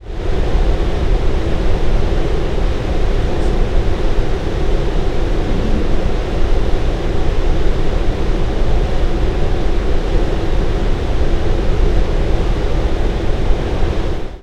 Sound file 1.7 A constant sound of air pushed through the vent